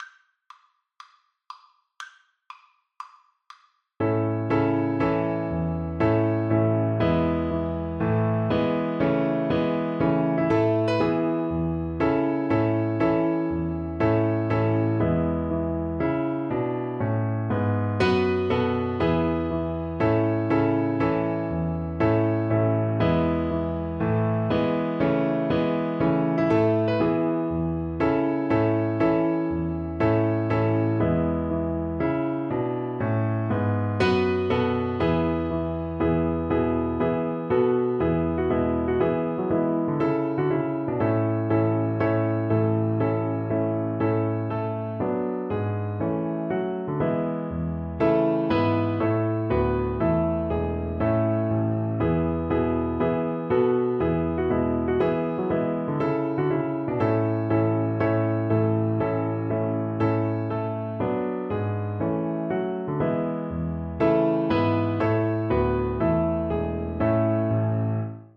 4/4 (View more 4/4 Music)
Db5-F#6
Allegro (View more music marked Allegro)
A major (Sounding Pitch) (View more A major Music for Violin )
Violin  (View more Easy Violin Music)
Classical (View more Classical Violin Music)